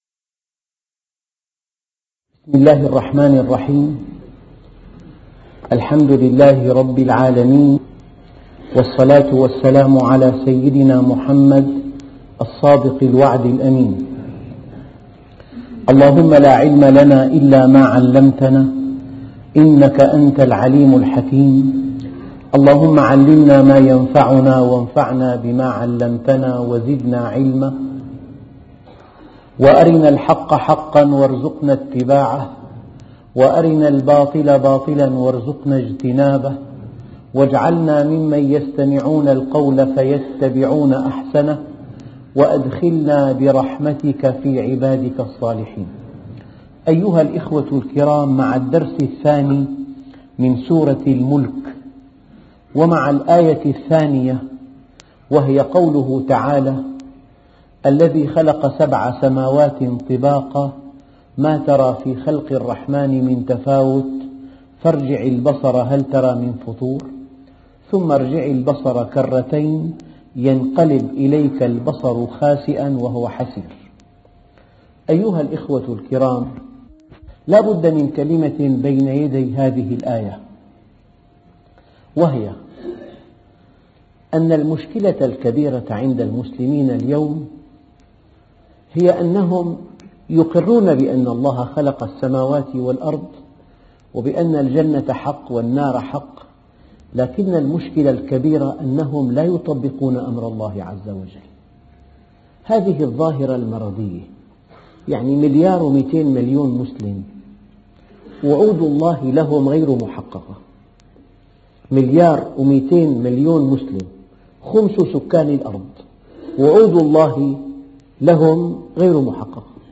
أرشيف الإسلام - ~ أرشيف صوتي لدروس وخطب ومحاضرات د. محمد راتب النابلسي